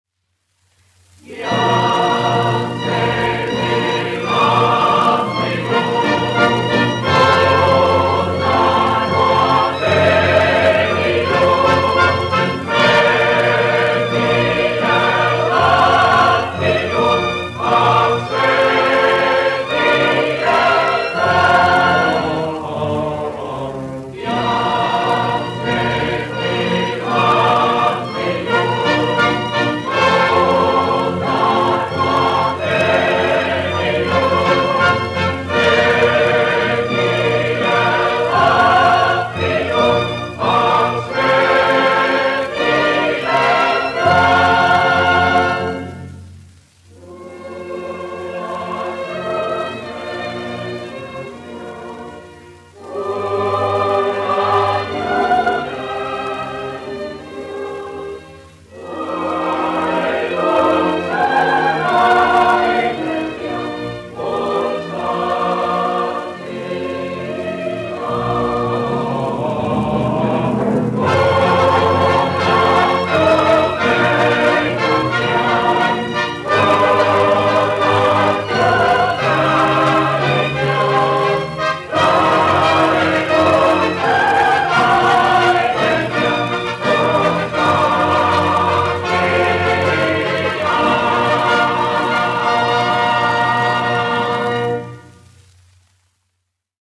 Reitera koris, izpildītājs
1 skpl. : analogs, 78 apgr/min, mono ; 25 cm
Nacionālās dziesmas un himnas
Kori (jauktie) ar orķestri
Skaņuplate